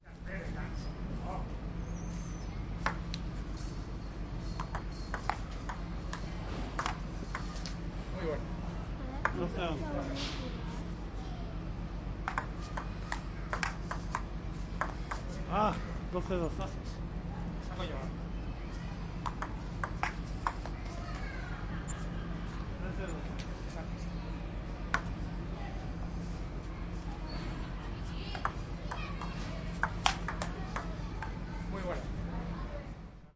乒乓球击球
描述：乒乓球被桨击中。
标签： 乒乓 反弹 命中 乒乓球
声道立体声